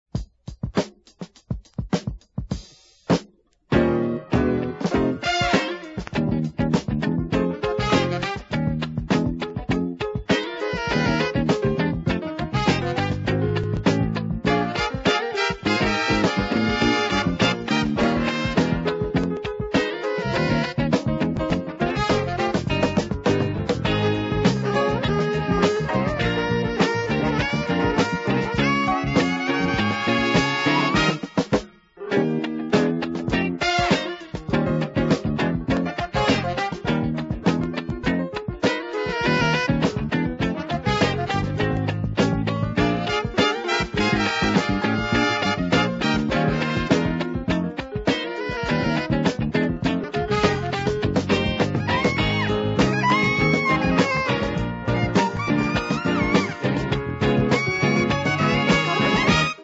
Heavy use of sax keeps the strong groove going.